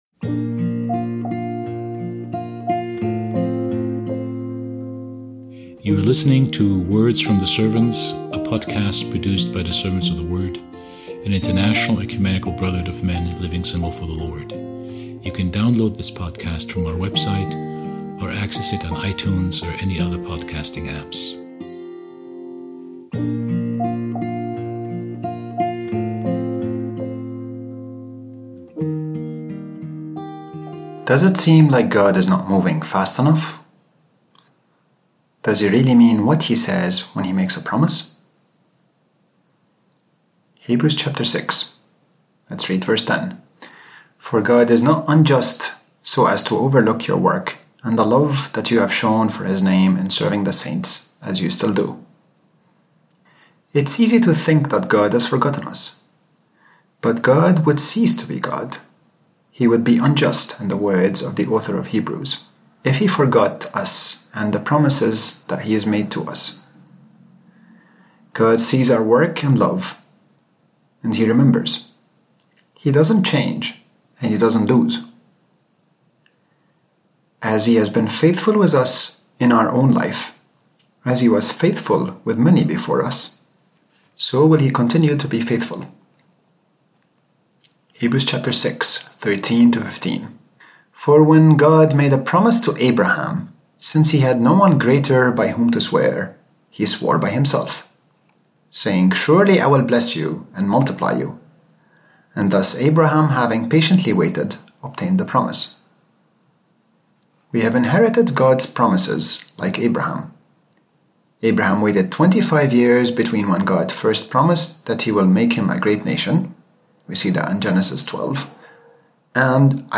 Throughout the 40 Days of Lent, our Words from the Servants podcast will feature brothers from around the world as they give daily commentary on the Pentateuch and the book of Hebrews.